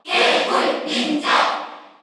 Category: Crowd cheers (SSBU) You cannot overwrite this file.
Greninja_Cheer_Korean_SSBU.ogg.mp3